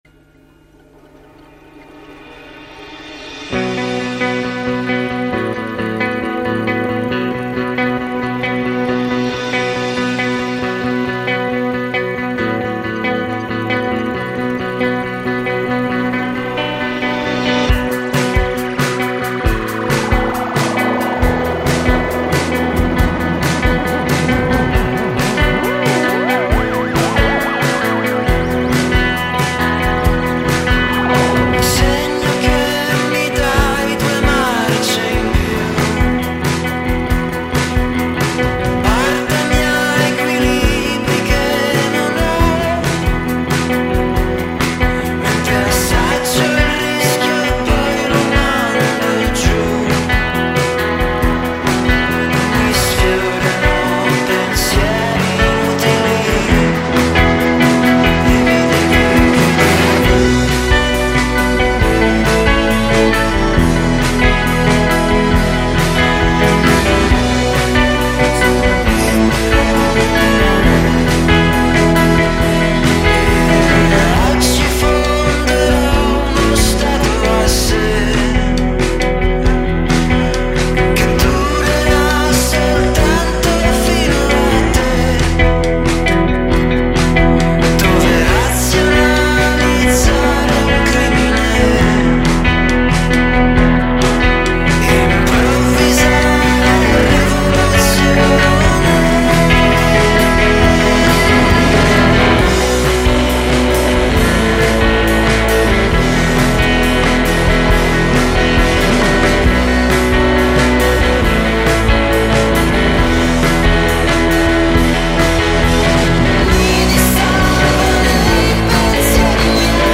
psych pop/rock
al muro sonoro chitarra / batteria
sonorità elettroniche e synth